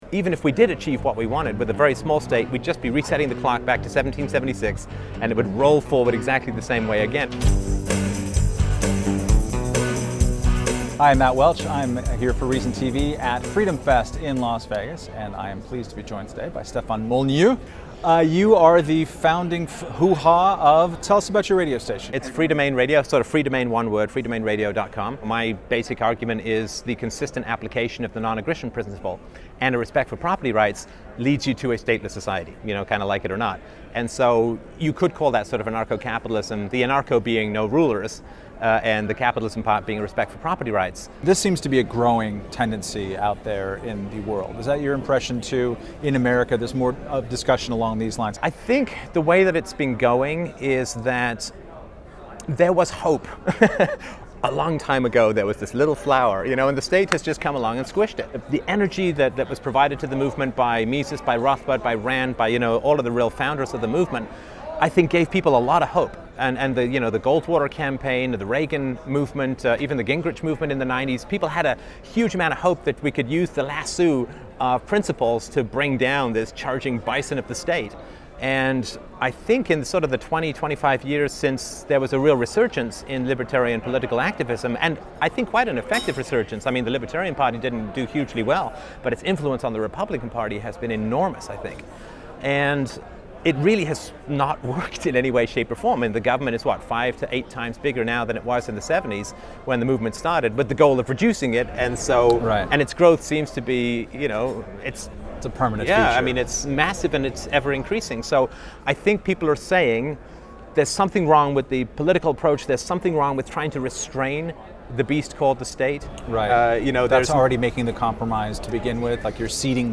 Reason magazine's Matt Welch sat down with Molyneux at FreedomFest 2012 and discussed why he thinks the libertarian ideal of a small state will inevitably grow, and why the absence of any form of the state is the only answer.
Held each July in Las Vegas, FreedomFest is attended by around 2,000 limited-government enthusiasts and libertarians a year.